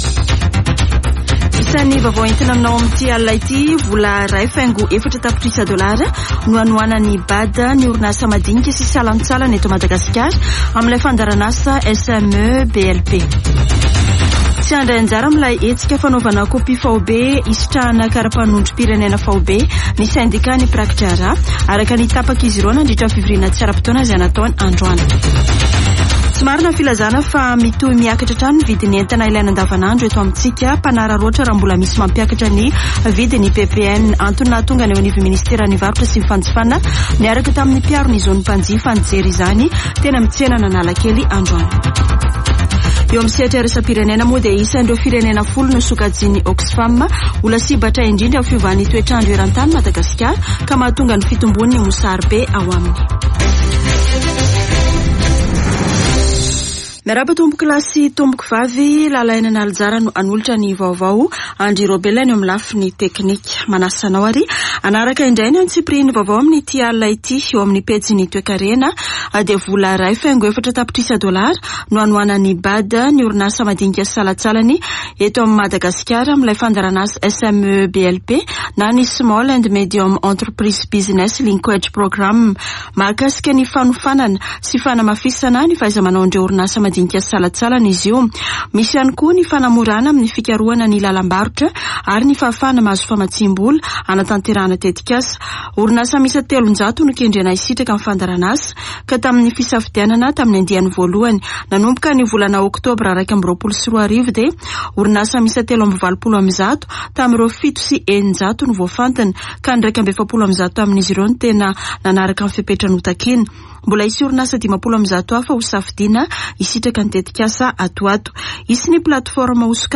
[Vaovao hariva] Zoma 16 septambra 2022